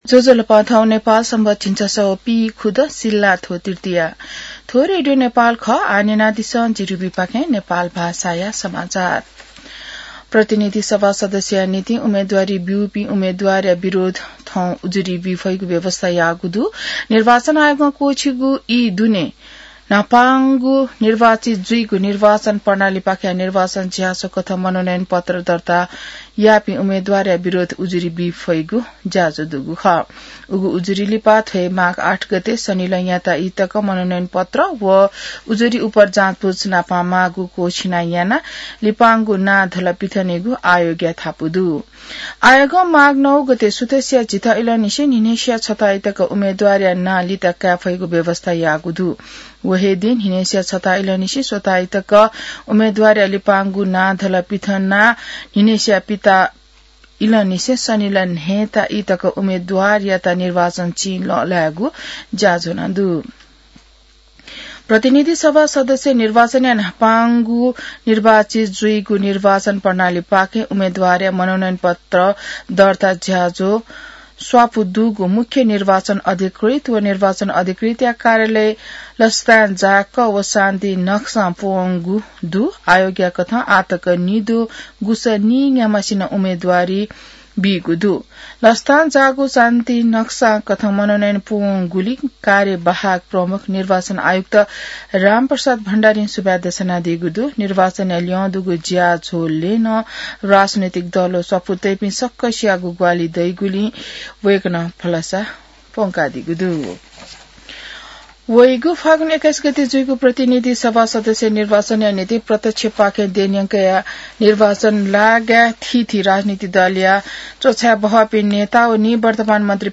नेपाल भाषामा समाचार : ७ माघ , २०८२